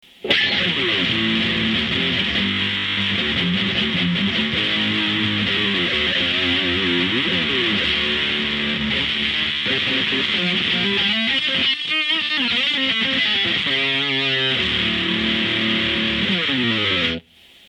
PANTERAのダイムバッグ・ダレルが望んだヘビーでシャープでアグレッシブなディストーションサウンドを実現したモデル。